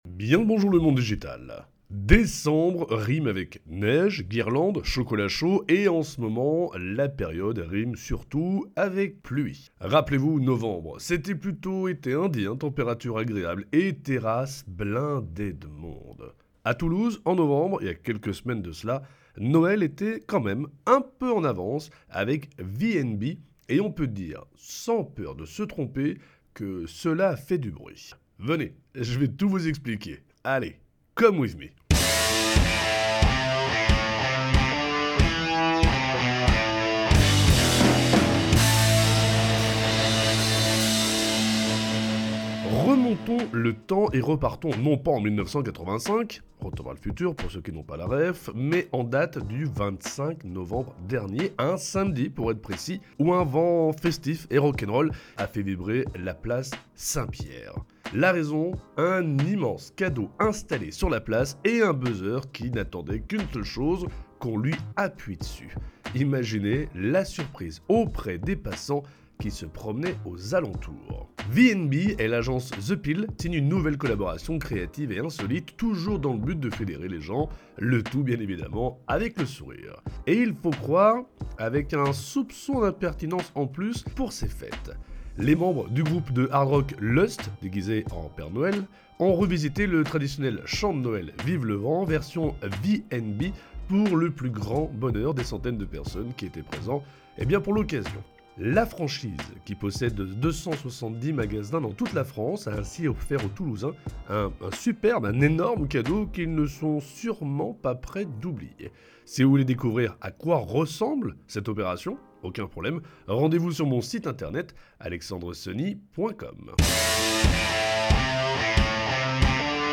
Remontons le temps et repartons non pas en 1985 (« Retour vers le futur » pour ceux qui n’ont pas la ref’), mais en date du 25 novembre dernier, un samedi pour être précis, où un vent festif et rock’n’roll a fait vibrer la Place Saint Pierre.
Les membres du groupe de hard rock LUST (déguisés en Père Noël of course) ont revisité le traditionnel chant de Noël, “Vive le vent”, version V and B pour le plus grand bonheur des centaines de personnes qui étaient présents pour l’occasion.